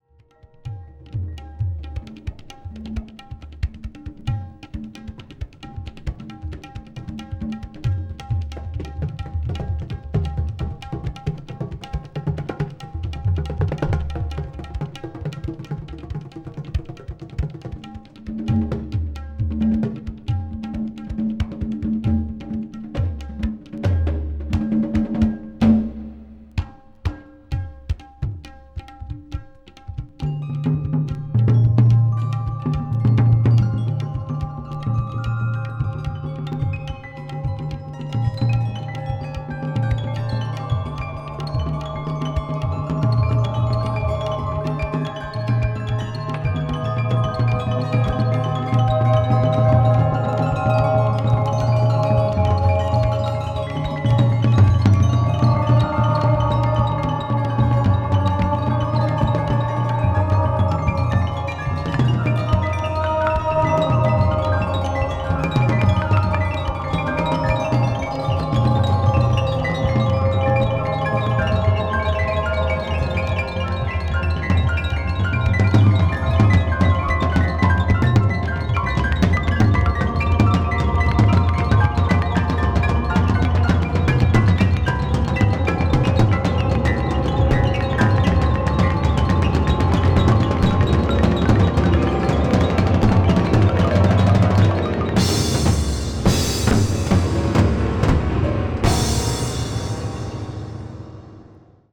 arab   contemporary jazz   ethnic jazz   world music